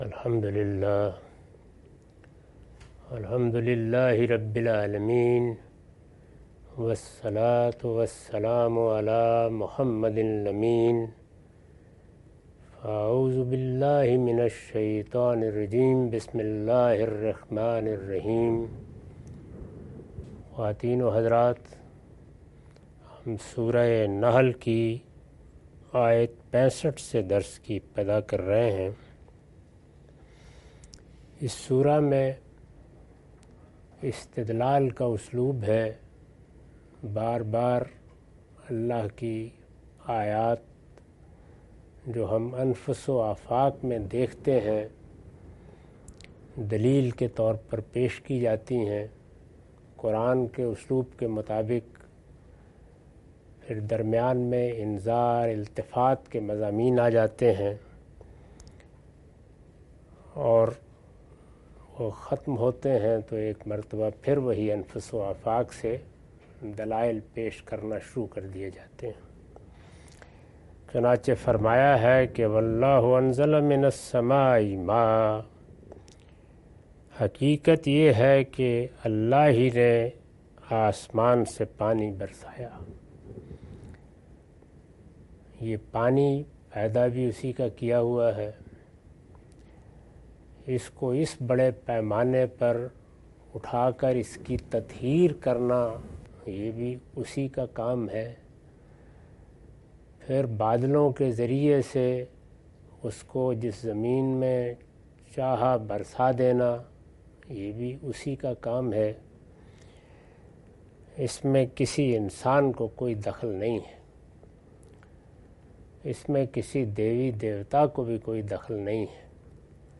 Surah Al-Nahl- A lecture of Tafseer-ul-Quran – Al-Bayan by Javed Ahmad Ghamidi. Commentary and explanation of verses 65-67.